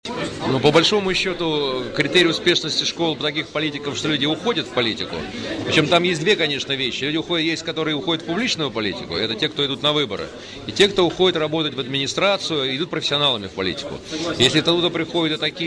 На вопрос корреспондента ИА «СеверИнформ» о том, по каким критериям можно судить об успешности и эффективности организованных на уровне Вологодской области и города Вологды молодежных парламентов, как своеобразных школ самоуправления, господин Цыпляев ответил, что успешны эти проекты тогда, когда после них люди идут в политику.